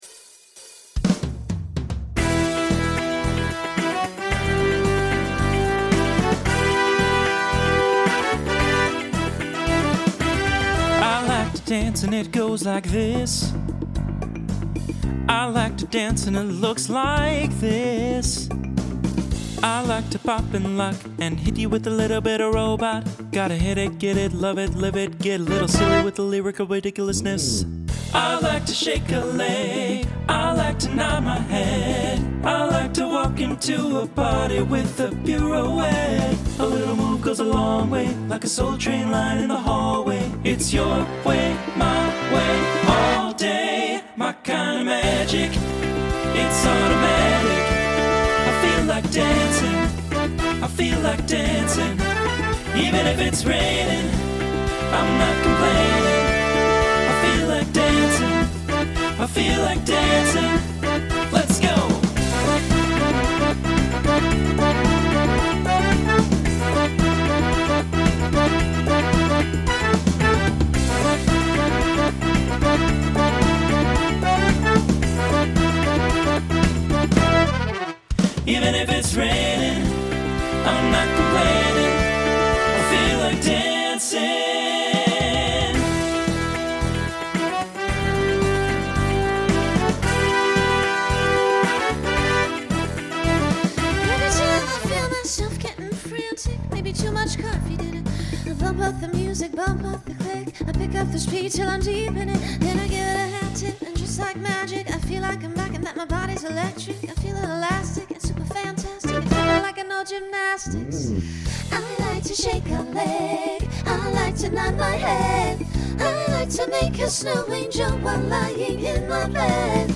Starts TTB, then SSA, then finishes SATB.
Genre Pop/Dance
Transition Voicing Mixed